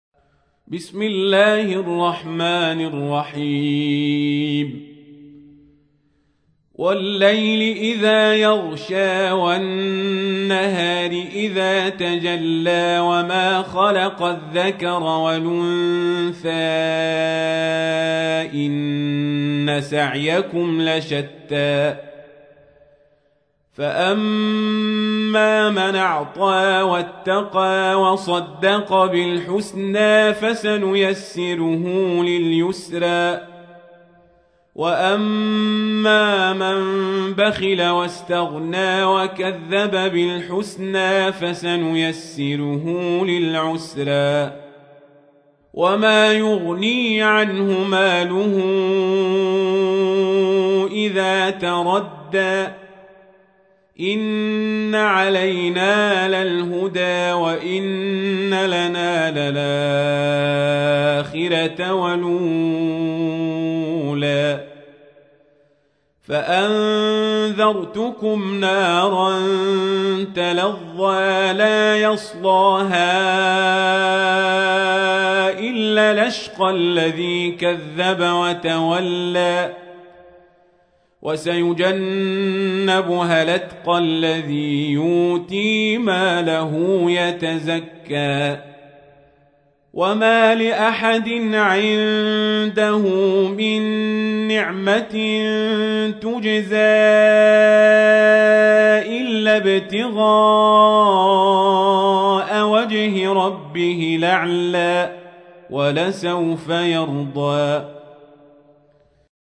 تحميل : 92. سورة الليل / القارئ القزابري / القرآن الكريم / موقع يا حسين